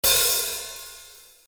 今回は、あらかじめ用意した HiHat 音を使います。
Open あるいは、ハーフOpenぎみの音です。
Hat_04.mp3